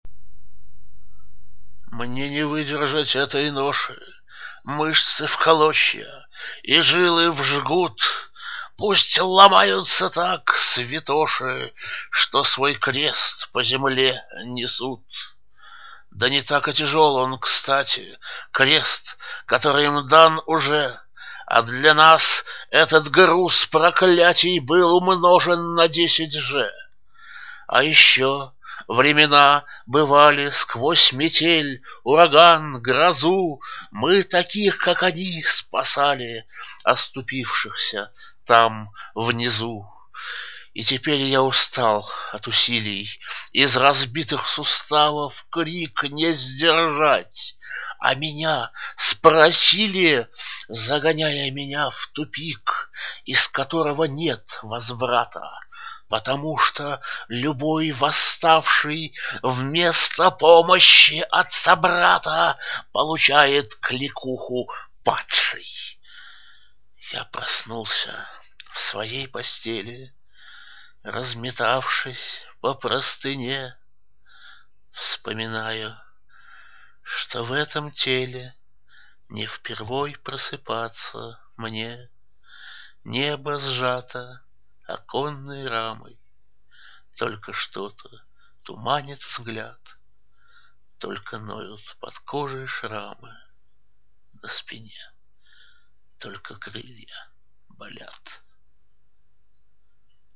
в авторском исполнении